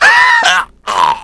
deathc52.wav